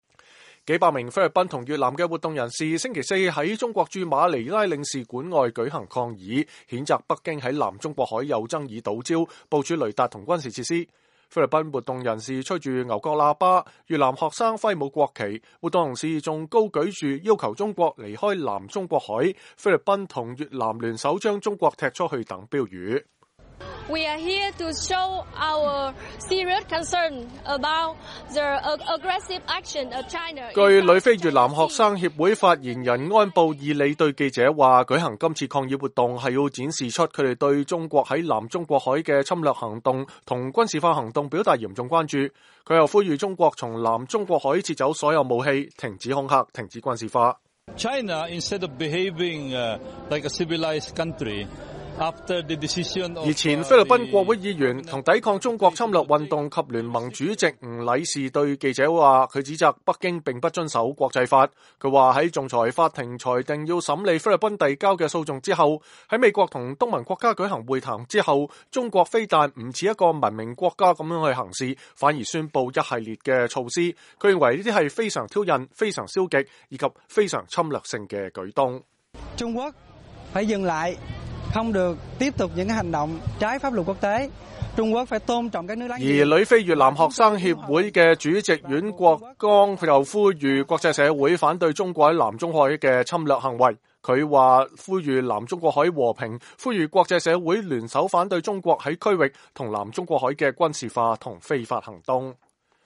數百名菲律賓和越南的活動人士星期四在中國駐馬尼拉領事館外舉行抗議，譴責北京在南中國海有爭議島礁部署雷達和軍事設施。菲律賓活動人士吹著牛角喇叭，越南學生揮舞國旗。